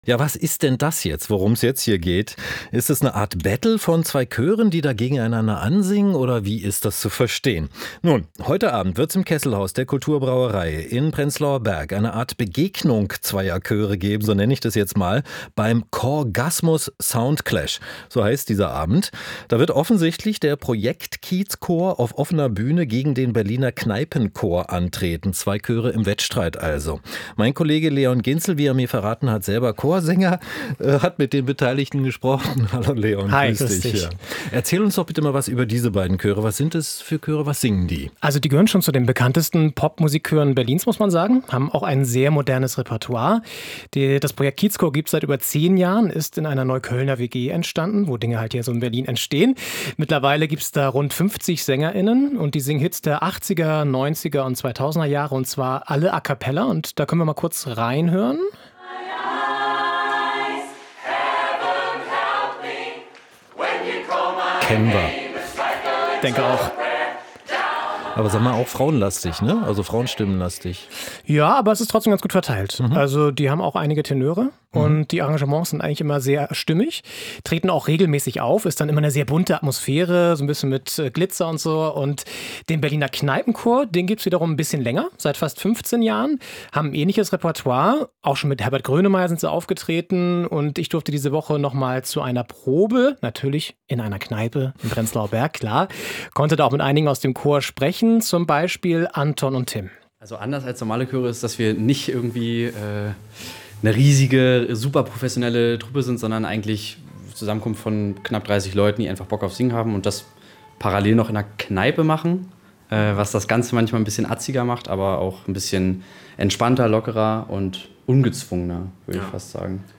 In diesem Jahr tritt also der Berliner Kneipenchor gegen den Kiezchor an. Wie das klingt?